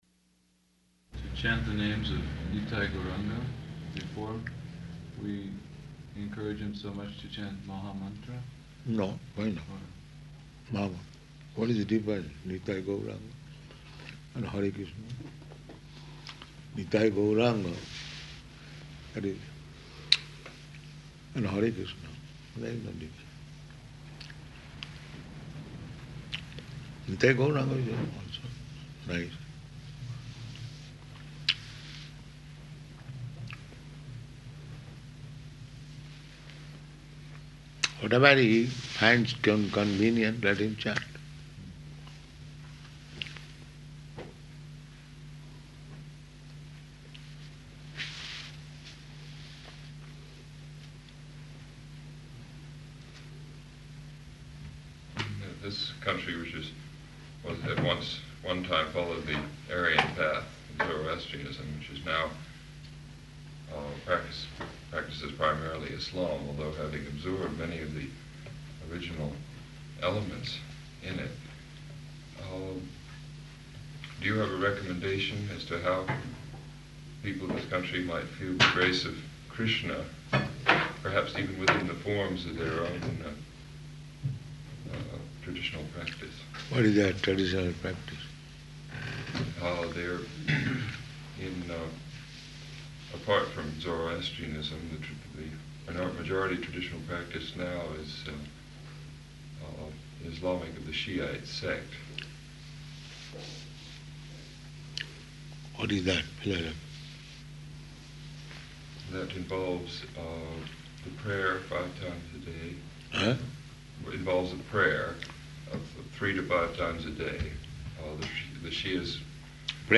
Room Conversation with Yoga Student and Other Guests
Type: Conversation
Location: Tehran